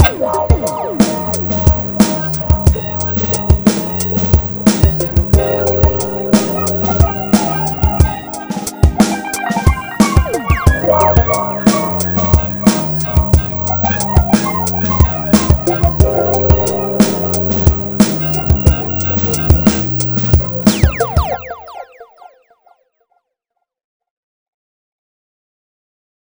This was a quick loop (quick as in I jammed solos and riffs over this loop for a couple hours lolll) but it’s using mainly both of the new Gadgets